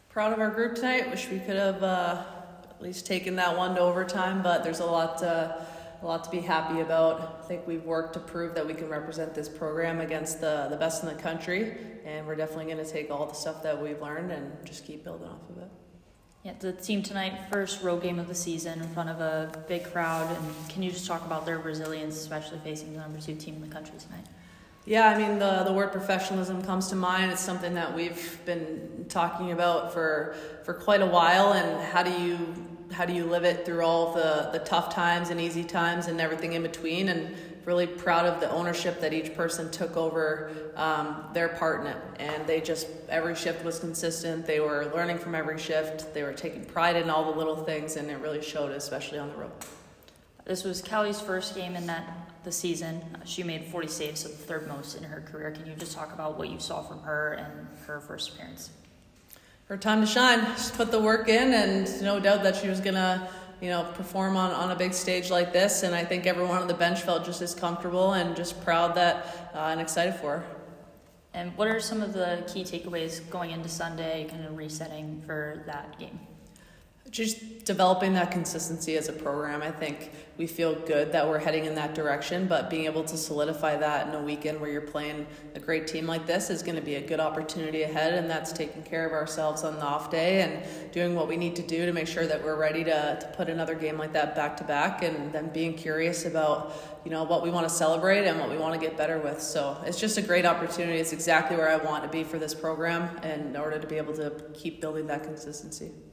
Minnesota Postgame Interview